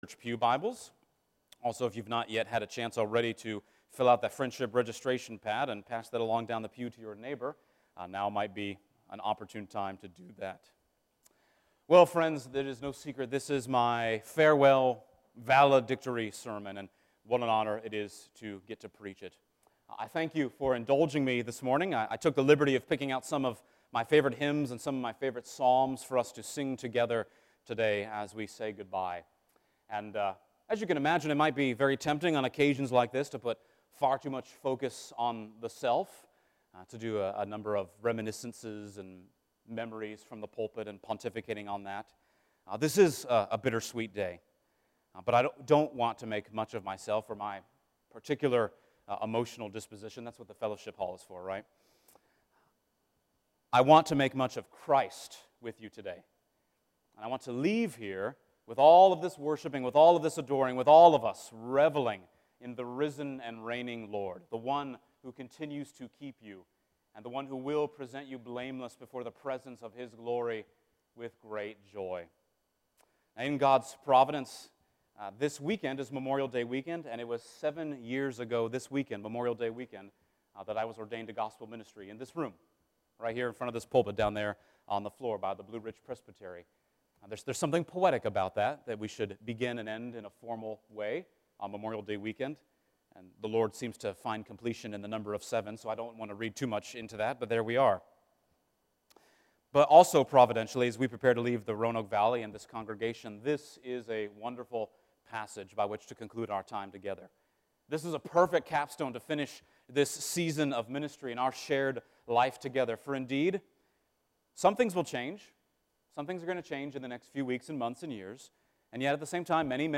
Jude Passage: Jude 1:24-25 Service Type: Sunday Morning %todo_render% « Jude 24-25